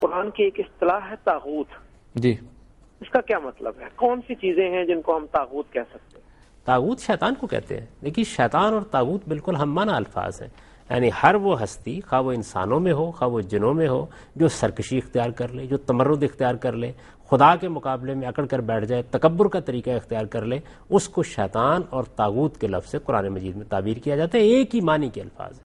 Answer to a Question by Javed Ahmad Ghamidi during a talk show "Deen o Danish" on Dunya News TV